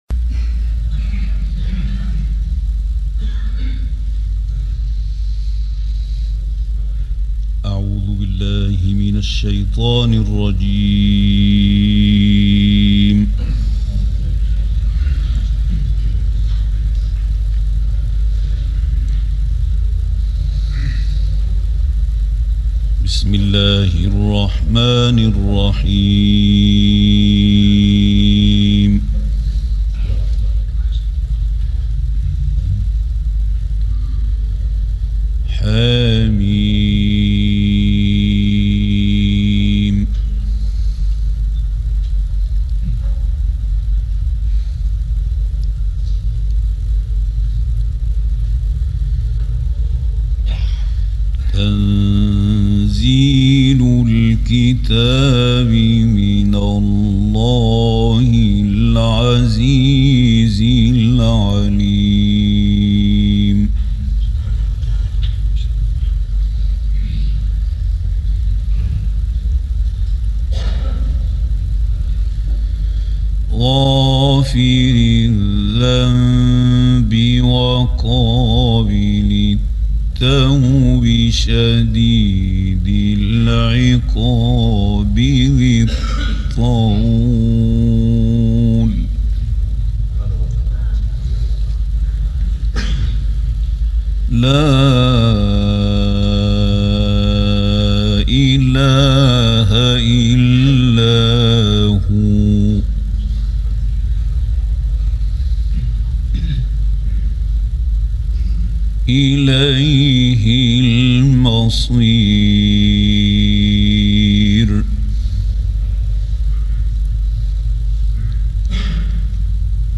یکصدمین سالروز تولد «محمود خلیل الحصری» + تلاوت کمتر شنیده شده
این استاد مصری که در عین سادگی با صلابت با خشوع و معنامحور تلاوت می‌کرد، موفق شد در طی سالیان متمادی قرائت قرآن در کشورهای مختلف دنیا، ده‌ها نفر را به دین مبین اسلام، مشرف کند.